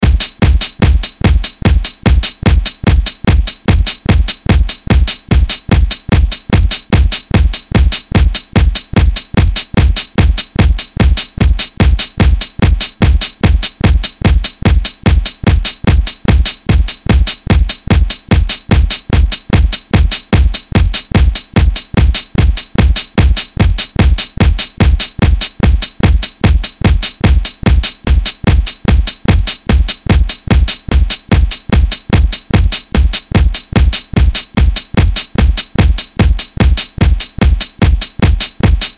ループサウンド。